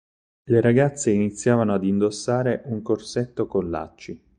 Read more alternative form of a for euphony, especially before /a/; to, at, in Frequency A1 Pronounced as (IPA) /a.d‿/ Etymology From Latin ad.